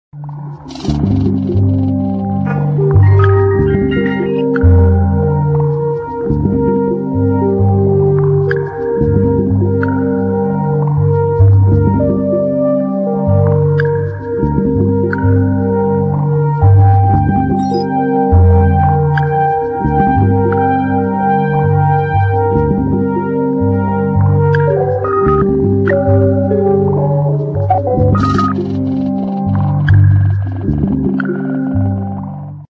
nervous slow instr.